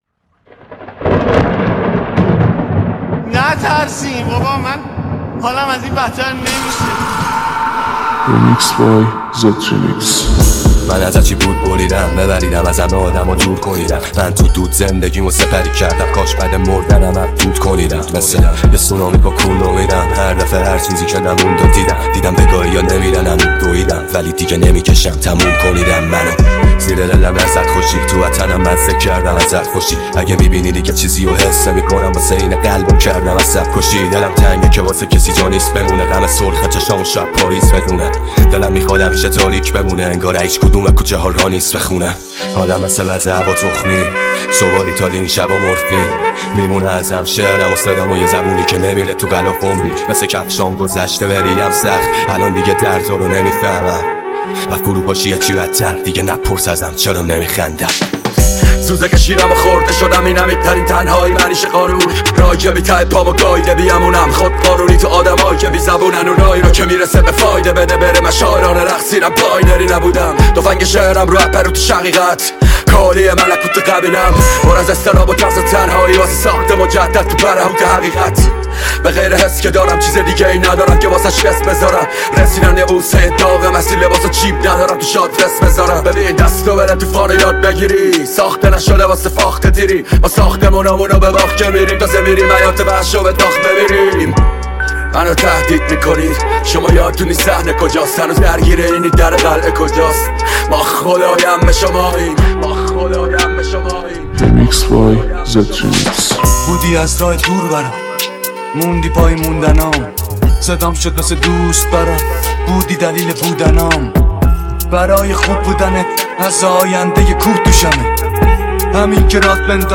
ریمیکس رپی